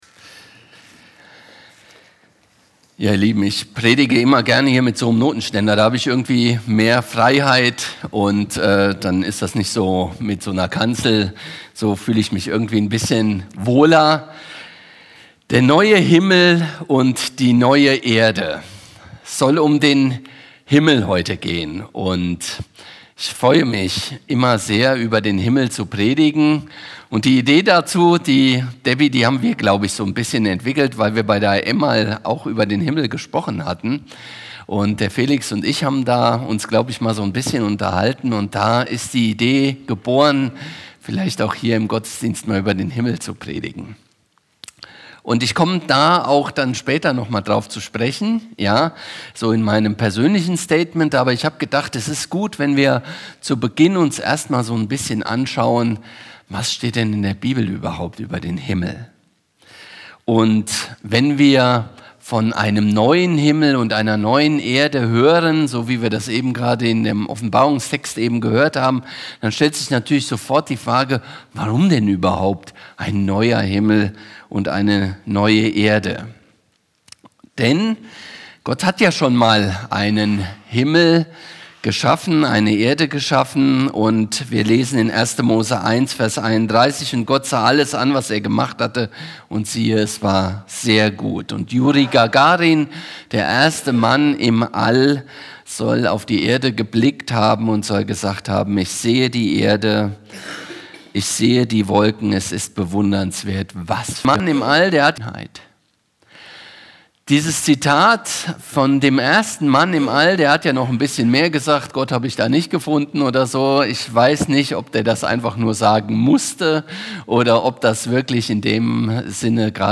FeG Steinbrücken – Predigten